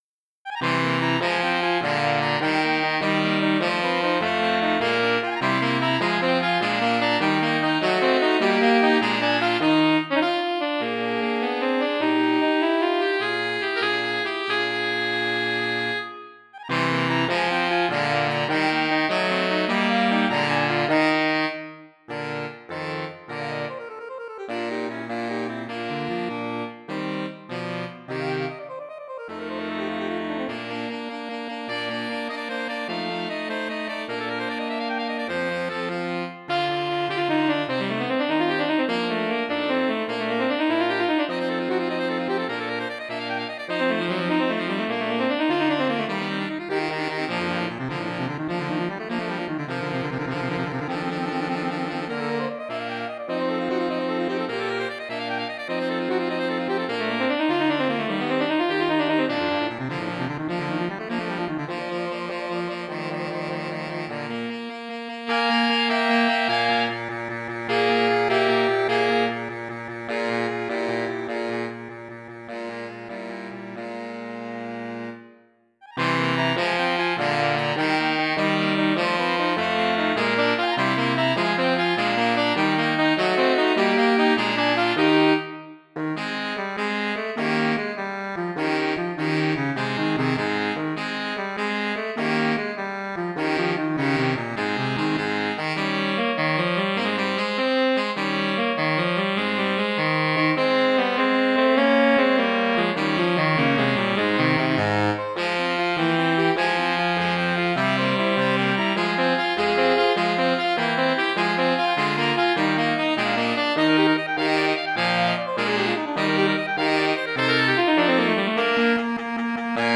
SATB. Allegro. Fast passages in all parts.